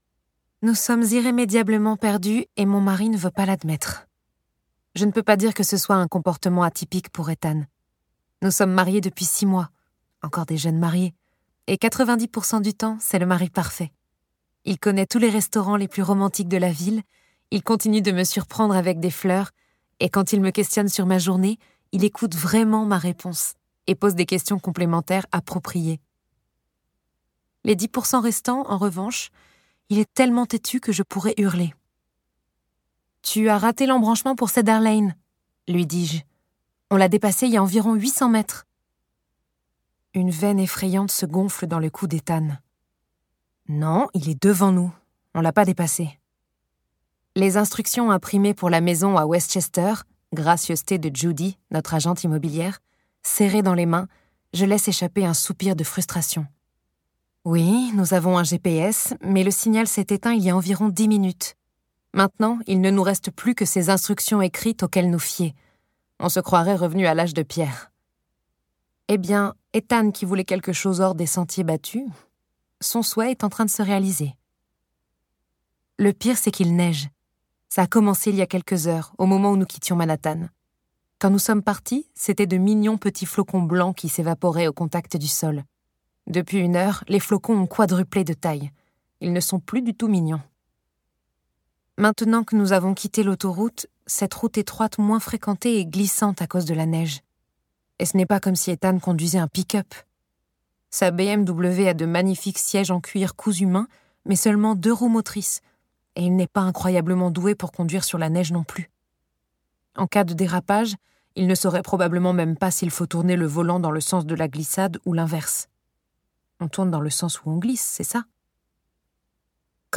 Get £2.85 by recommending this book 🛈 Un thriller déroutant porté par un envoûtant duo de comédiennes !